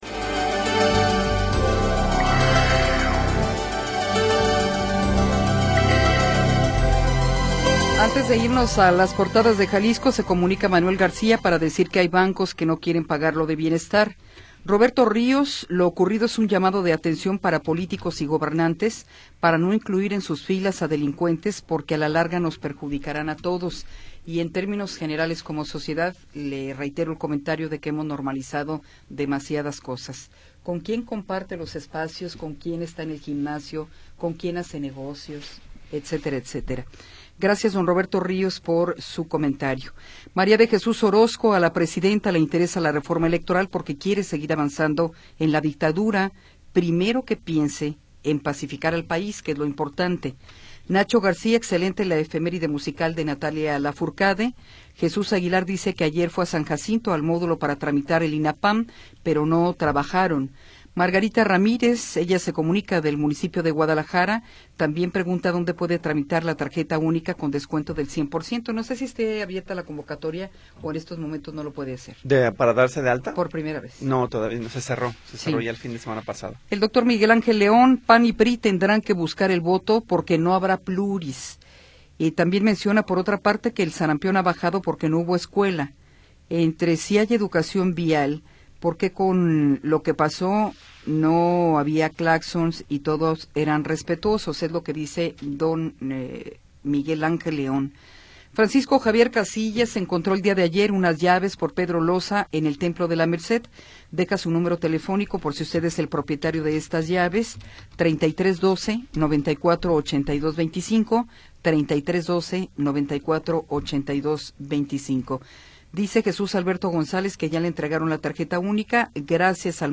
Tercera hora del programa transmitido el 26 de Febrero de 2026.